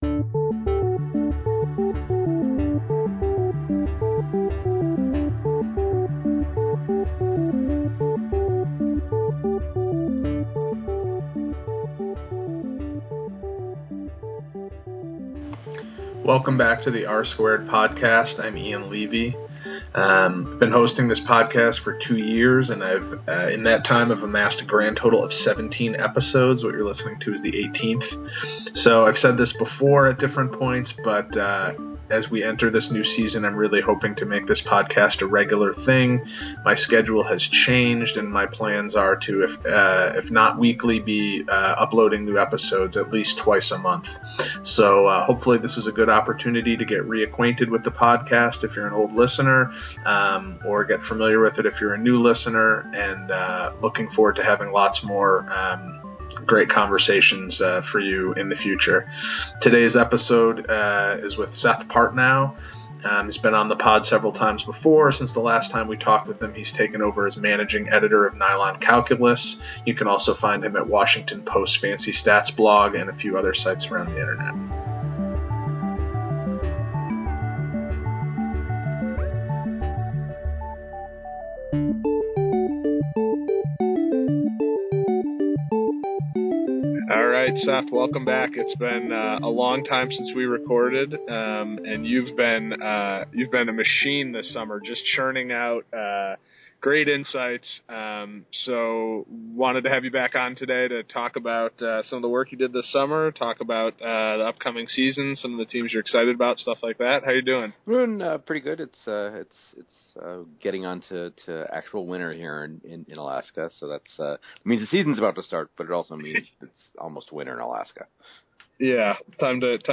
A relaunched and reinvigorated r-squared Podcast kicks off for the new NBA season. This episode features a conversation